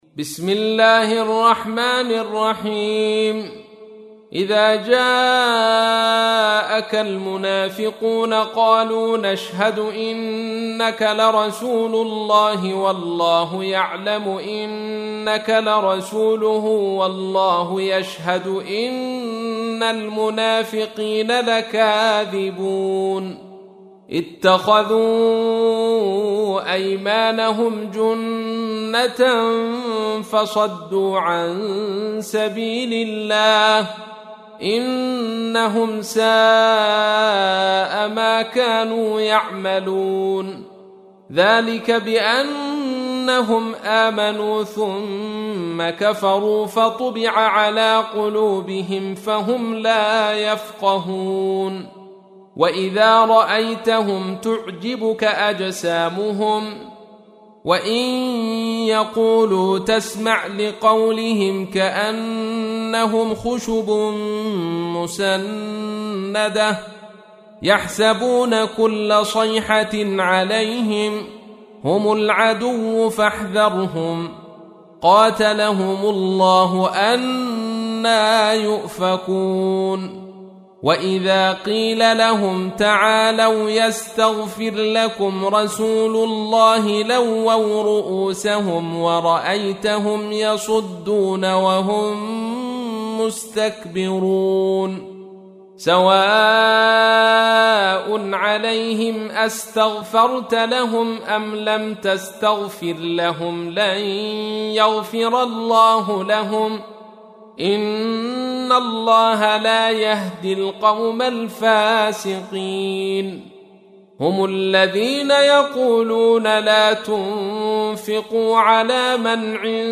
تحميل : 63. سورة المنافقون / القارئ عبد الرشيد صوفي / القرآن الكريم / موقع يا حسين